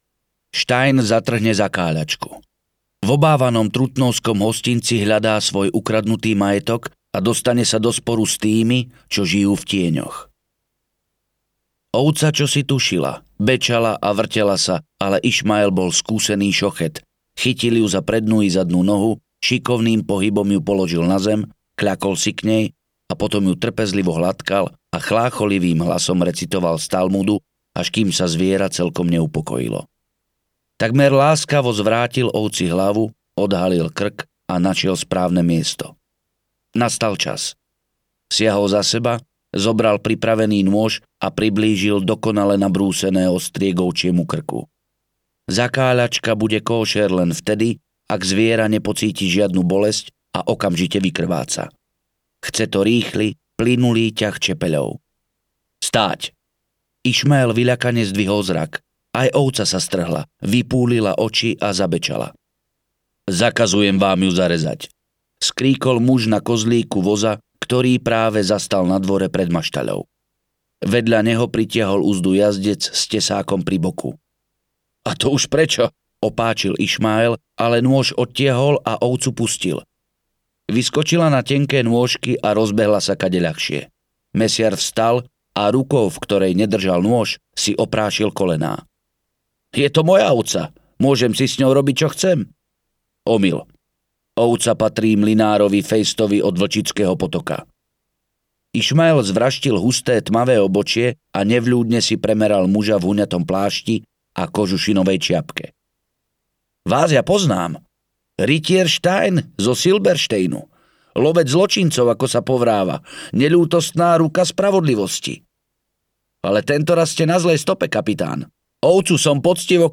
Les prízrakov audiokniha
Ukázka z knihy